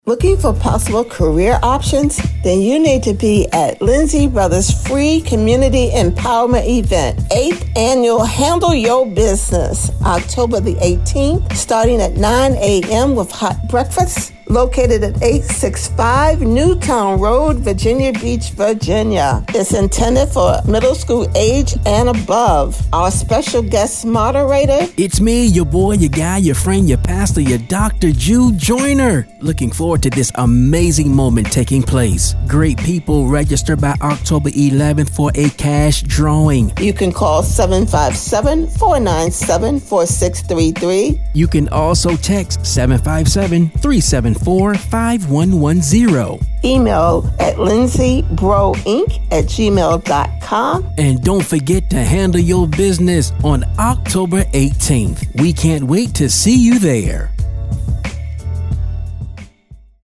Radio-Ad-HYB-2025.mp3